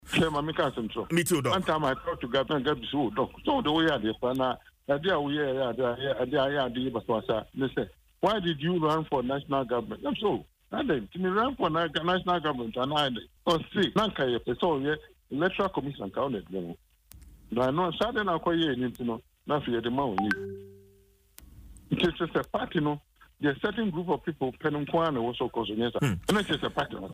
In an interview on Adom FM’s Dwaso Nsem, the former New Patriotic Party (NPP) member revealed that he contested the party’s Chairmanship position not because he needed the role but to get closer to Akufo-Addo and guide him on key decisions.